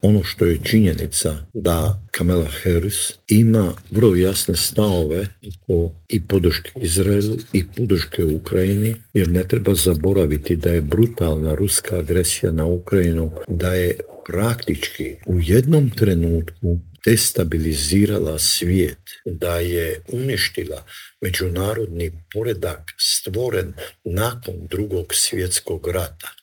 Cilj je bio eliminirati predsjednika Franju Tuđmana, a tog se dana u Intervjuu tjedna Media servisa prisjetio bivši ministar vanjskih poslova Mate Granić.